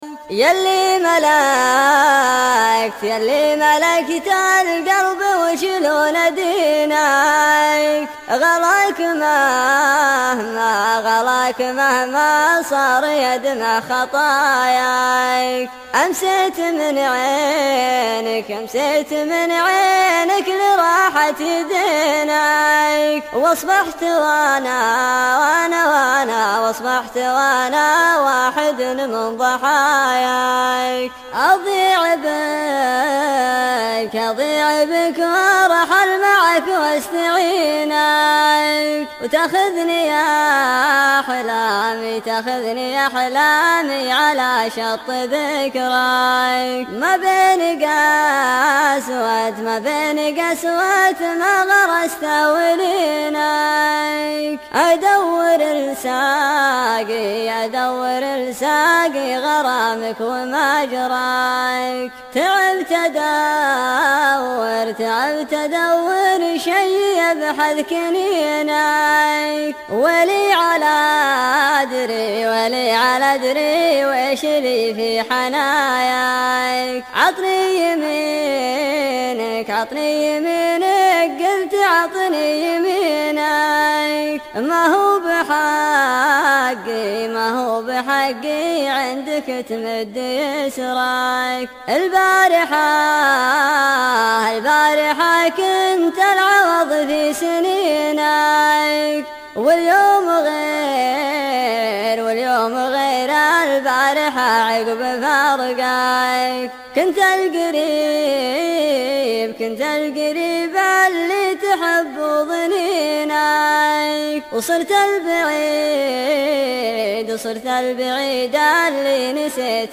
شيلة
مسرعة